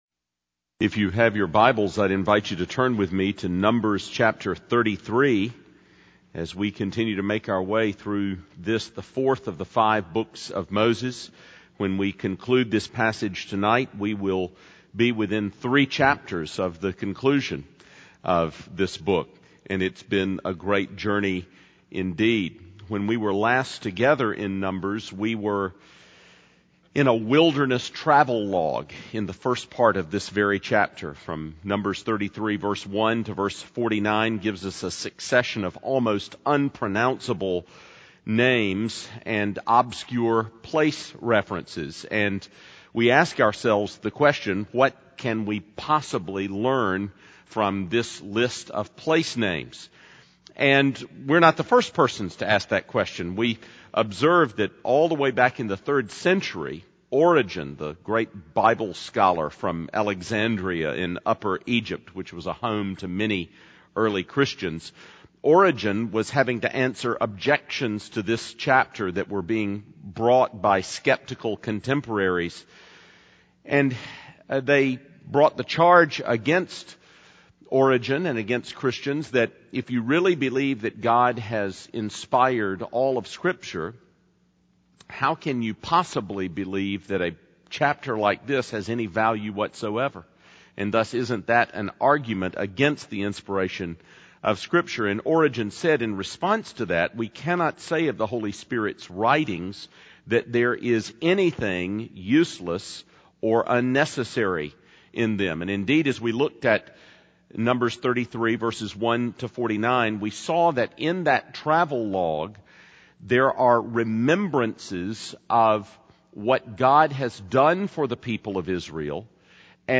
Sermon by on April 23, 2008 — Numbers 34:1-29